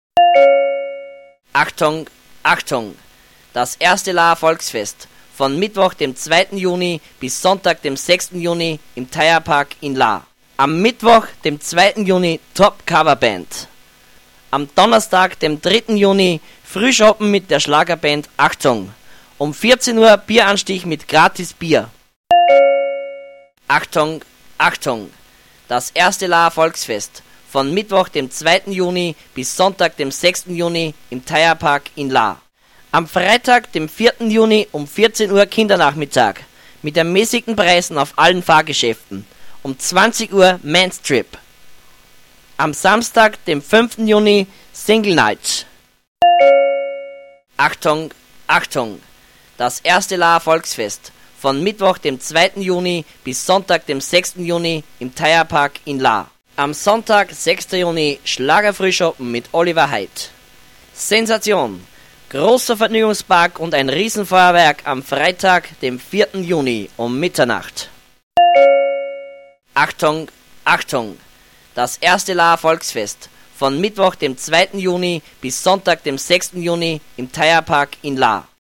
Die Ansage vom ersten Laaer Volksfest 2010 (Stra�en Werbung in einigen umliegenden Orten unter Verwendung eines Megaphons) kann >> HIER << herunter geladen werden (Mp3, 1,18 Megabyte)!